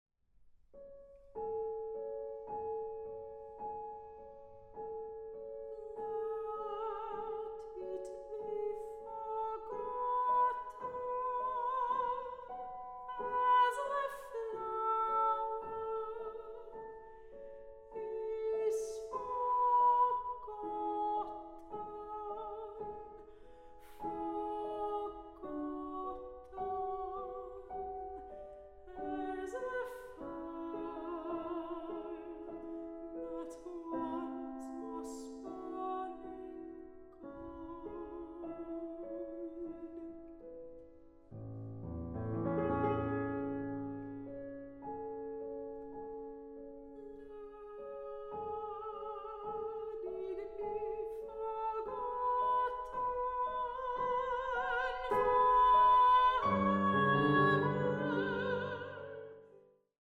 Soprano
Clarinet
Piano
Recording: Tonstudio Ölbergkirche, Berlin, 2023